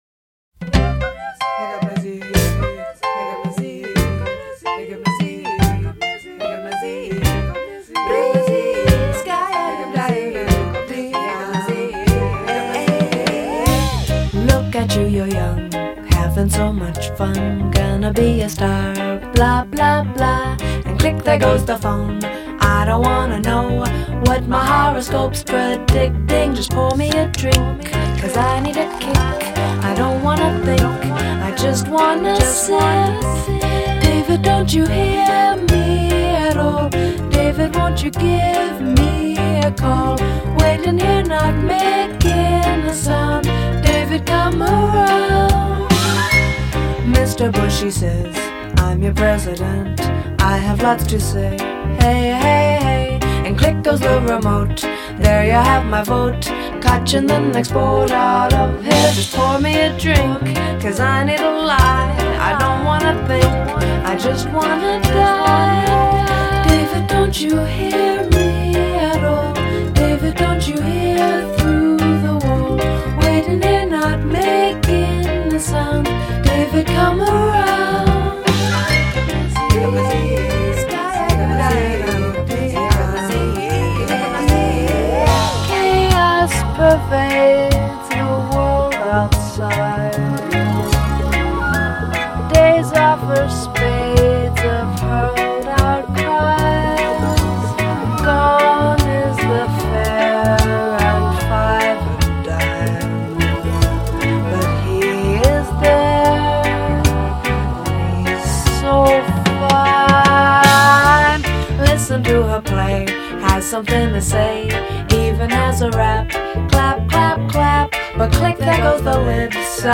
inventive, stylistically diverse and lyrically frank art pop